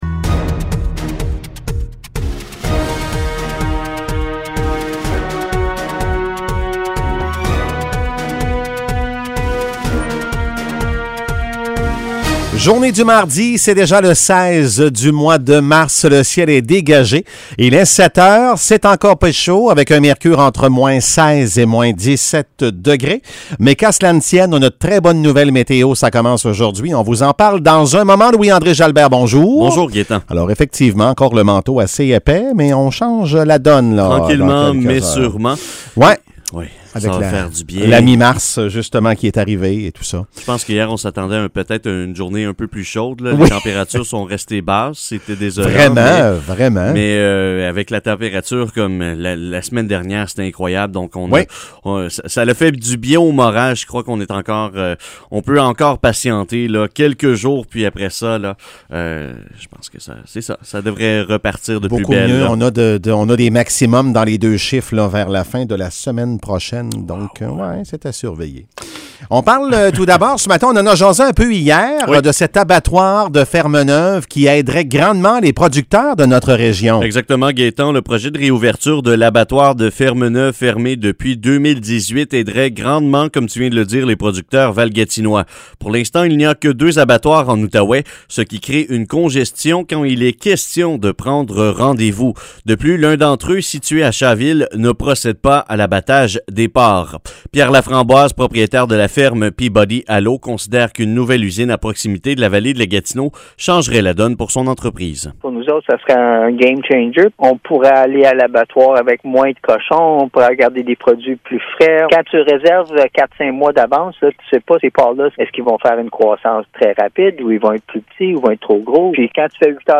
Nouvelles locales - 16 mars 2021 - 7 h